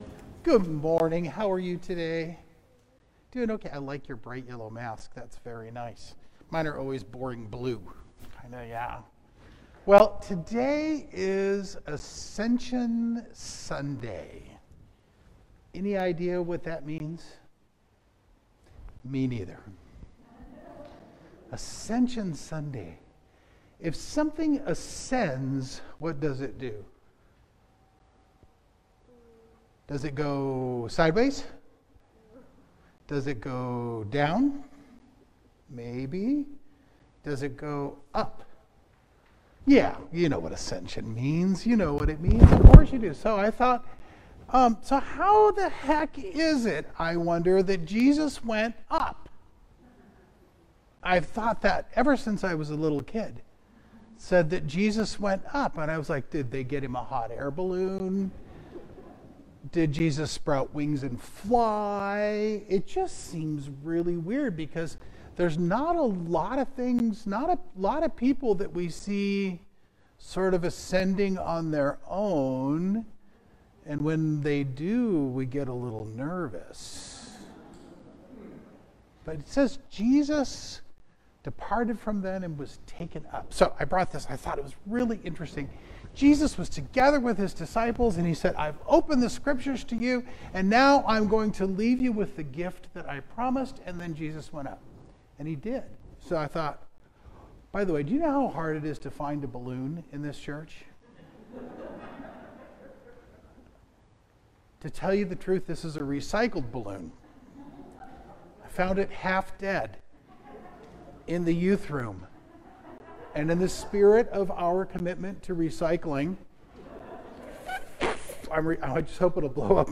Children’s Conversations—Easter 2023 (Year A)